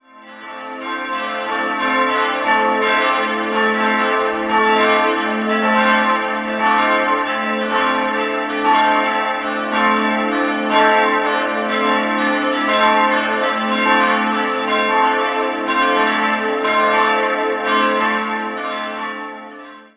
Sie wurde Anfang der 1960er-Jahre erbaut. 4-stimmiges Geläut: a'-c''-d''-e'' Die Glocken wurden 1968 von Friedrich Wilhelm Schilling in Heidelberg gegossen.